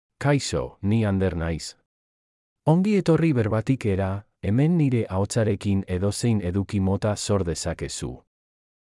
Ander — Male Basque AI voice
Ander is a male AI voice for Basque.
Voice sample
Listen to Ander's male Basque voice.
Male
Ander delivers clear pronunciation with authentic Basque intonation, making your content sound professionally produced.